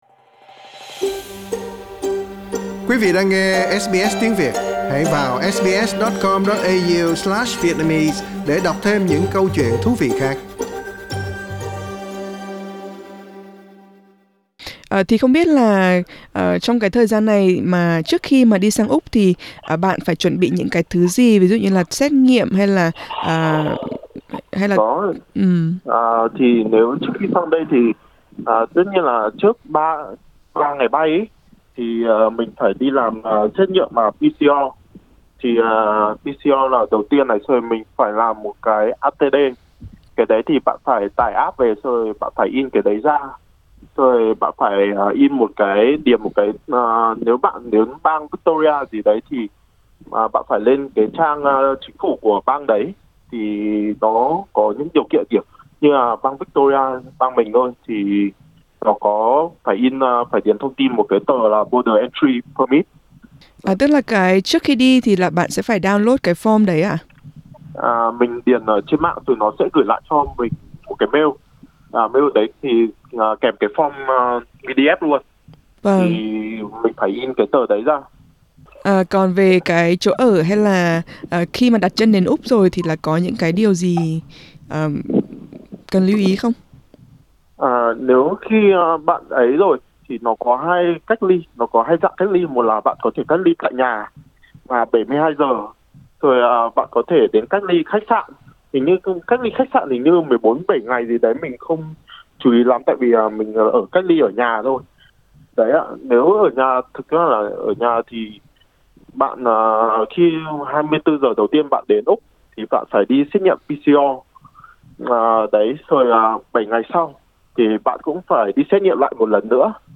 Mời quý vị bấm vào hình để nghe cuộc trò chuyện.